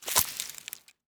harvest_3.wav